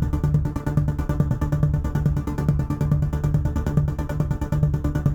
Index of /musicradar/dystopian-drone-samples/Tempo Loops/140bpm
DD_TempoDroneC_140-D.wav